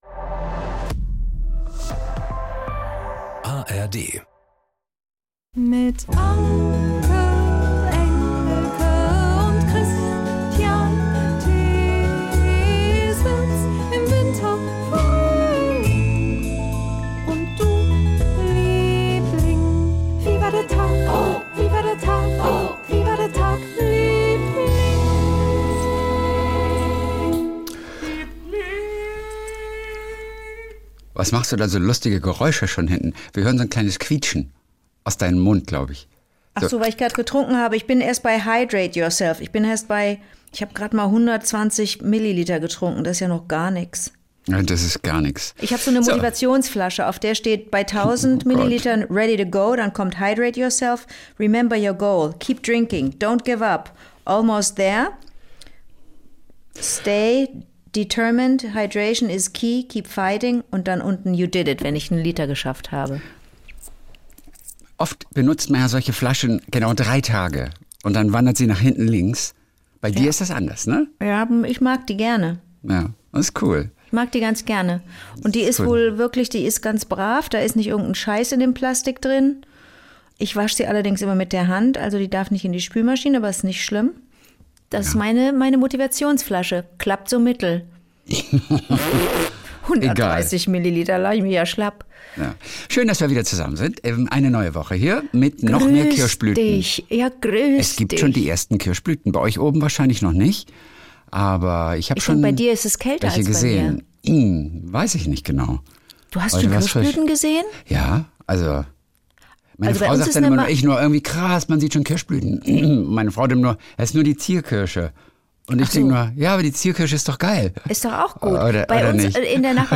Jeden Montag und Donnerstag Kult: SWR3-Moderator Kristian Thees und seine beste Freundin Anke Engelke erzählen sich gegenseitig ihre kleinen Geschichtchen des Tages.